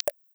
ClickyButton6.wav